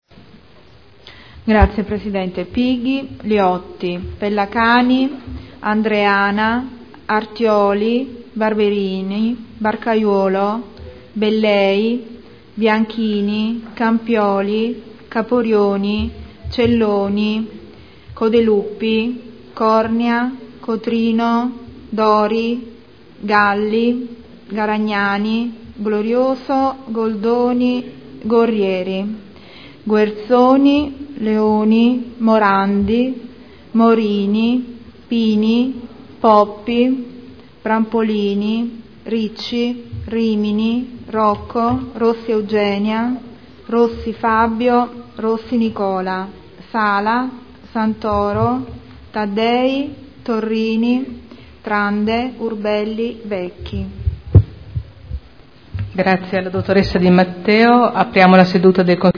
Segretario — Sito Audio Consiglio Comunale
Seduta del 24/09/2012 Appello.